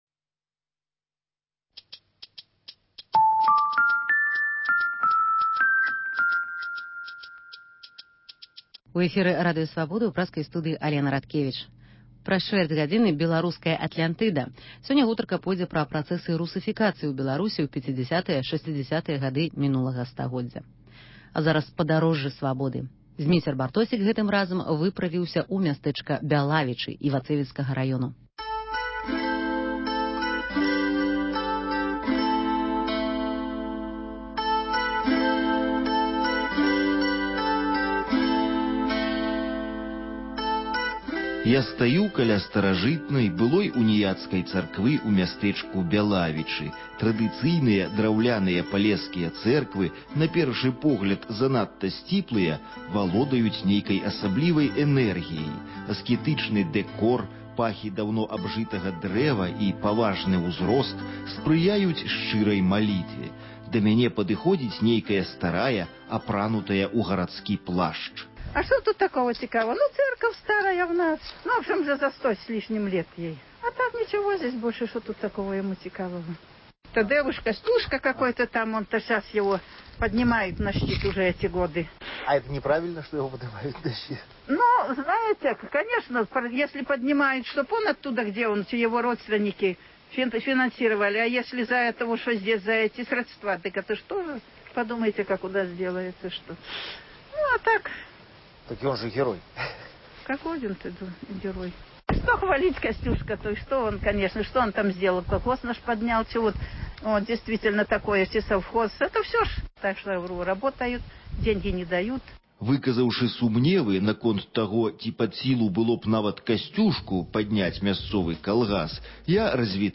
Паездкі нашых карэспандэнтаў па гарадах і вёсках Беларусі. Вёска Бялавічы Івацэвіцкага раёну.